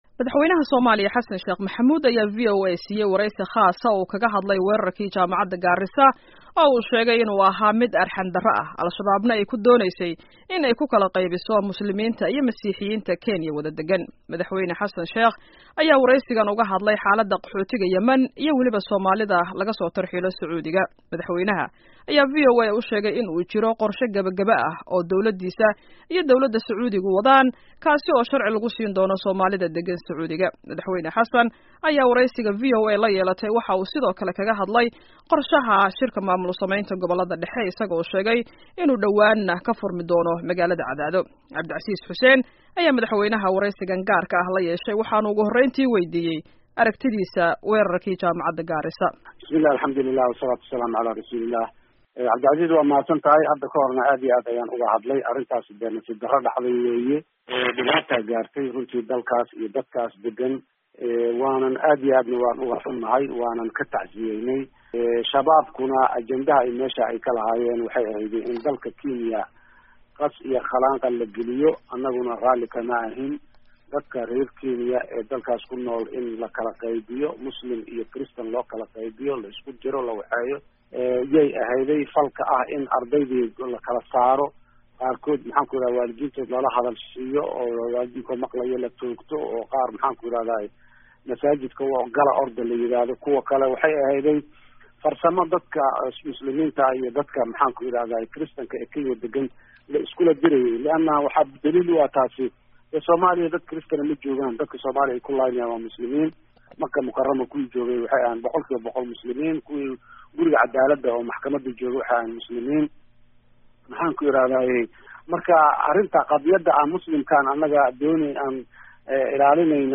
Dhageyso Wareysiga Madaxweynaha Soomaaliya